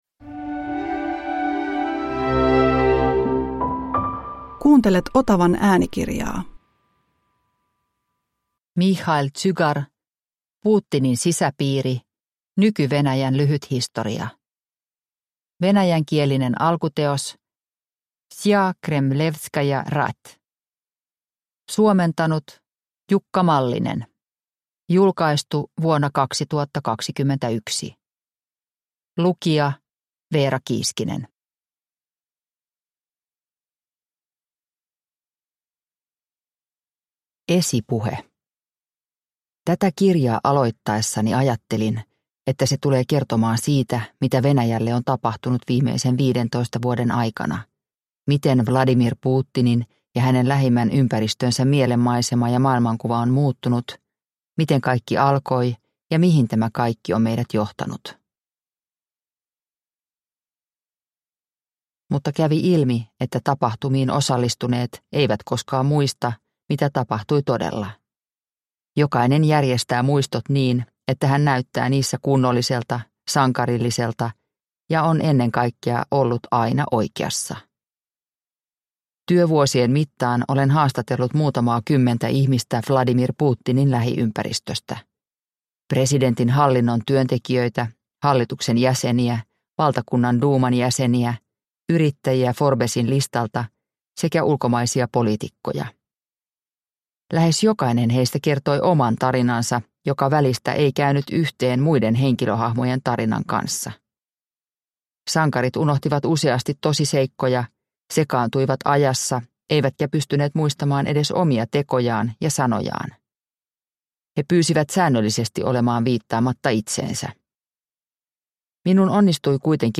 Putinin sisäpiiri – Ljudbok – Laddas ner